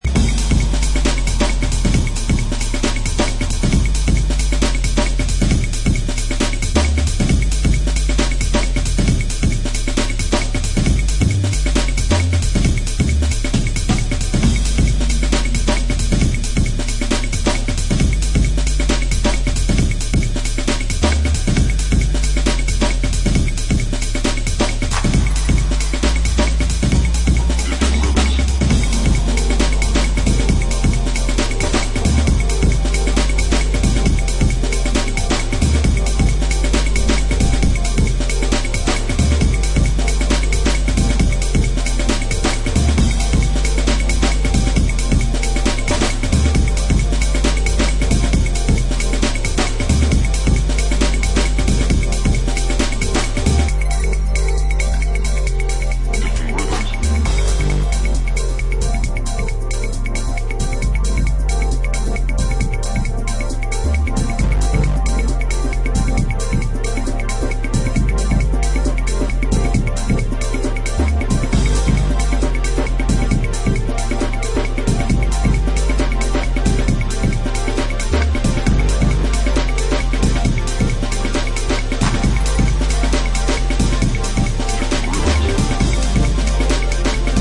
Italo